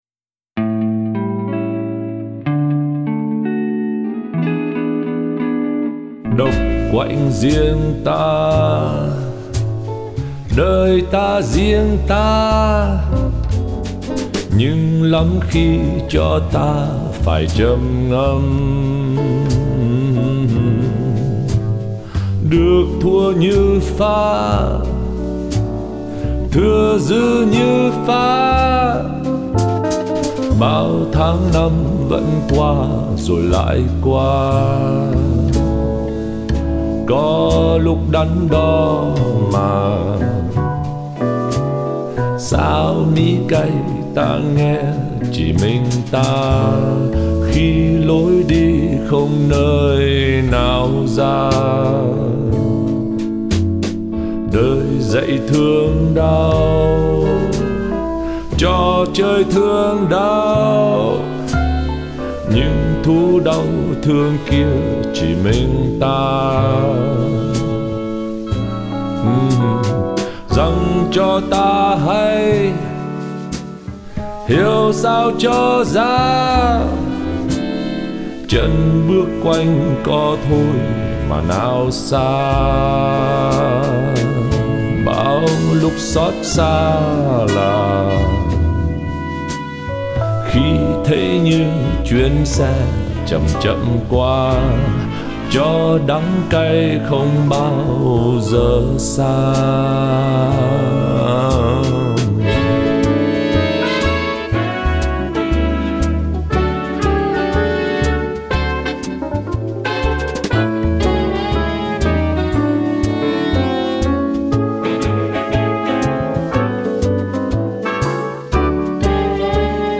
được viết theo điệu blues cổ điển
Giai điệu chỉ quanh quẩn với 3 hợp âm.